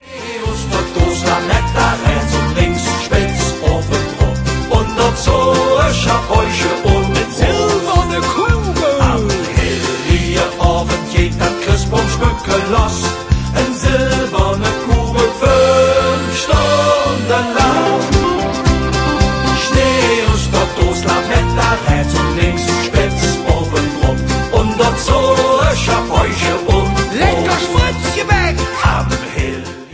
Advents- und Weihnachtsleedcher in Kölner Mundart